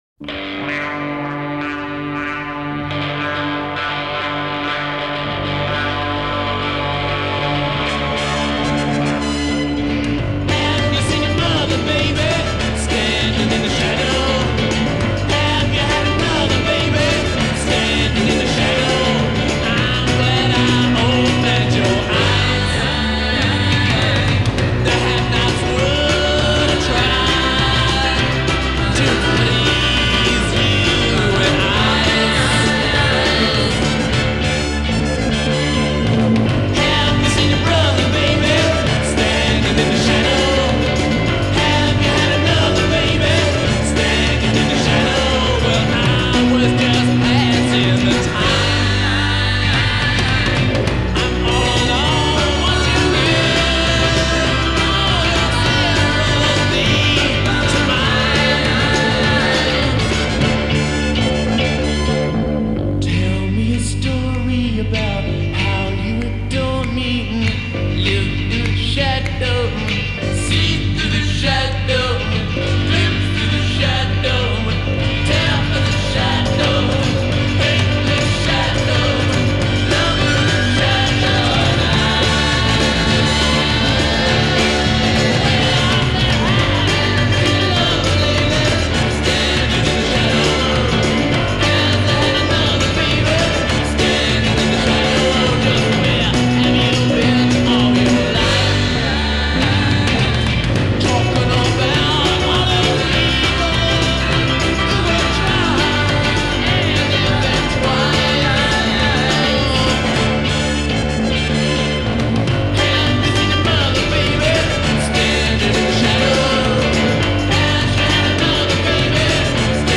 droning, wah-wahd
throat-ripping vocals, slightly too high a key perhaps